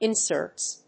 /ˌɪˈnsɝts(米国英語), ˌɪˈnsɜ:ts(英国英語)/